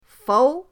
fou2.mp3